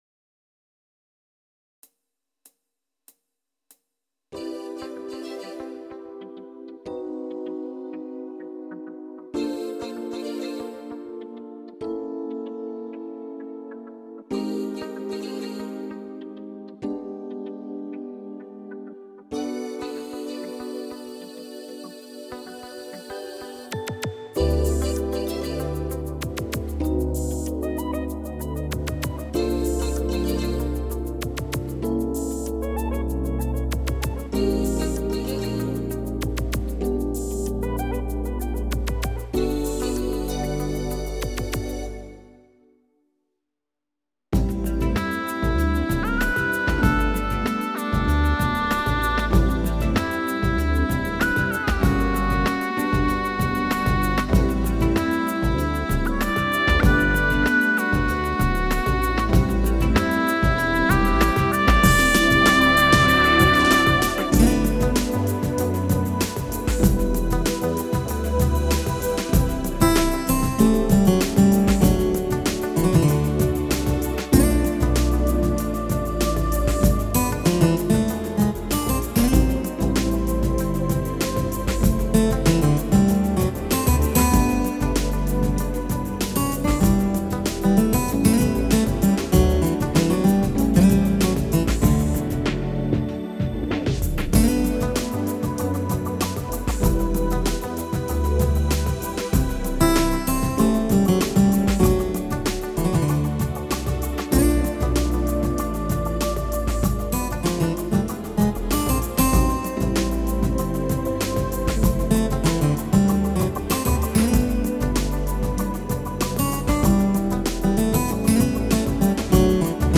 Classic